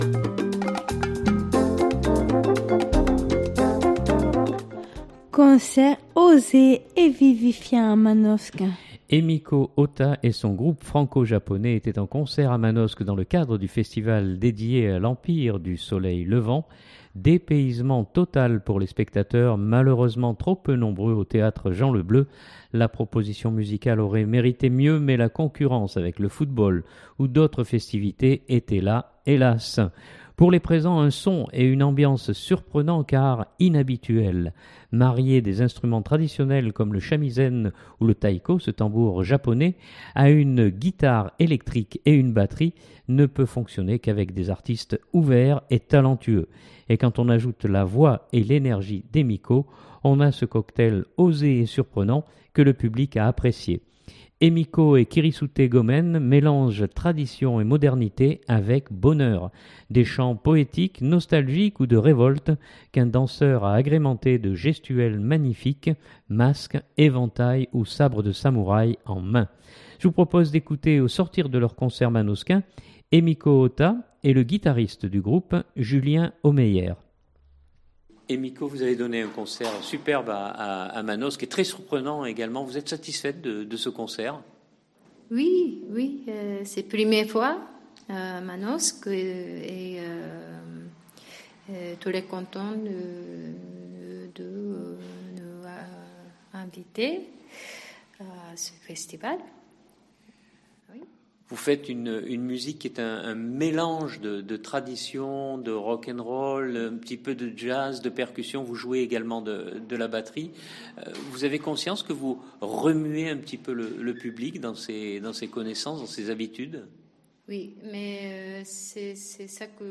Je vous propose d’écouter au sortir de leur concert manosquin
le guitariste du groupe